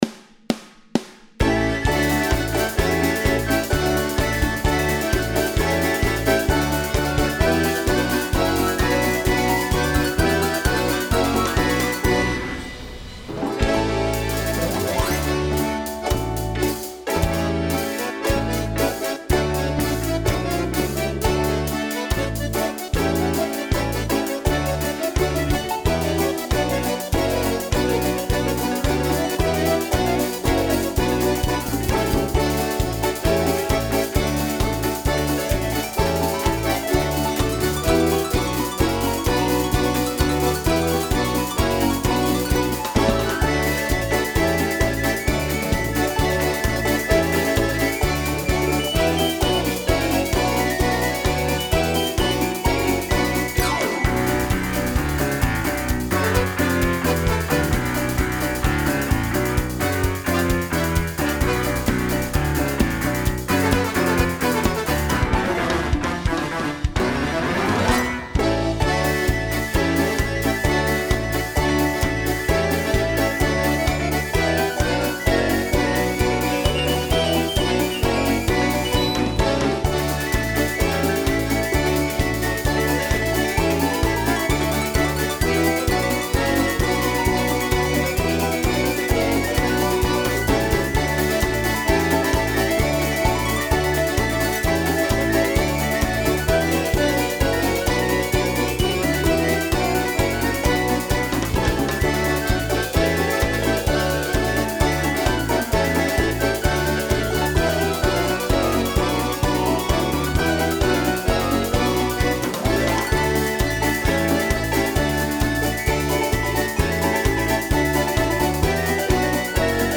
минусовка версия 45204